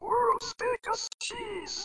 Unfortunately spelling it "chease" doesn't cause it to pronounce it any differently.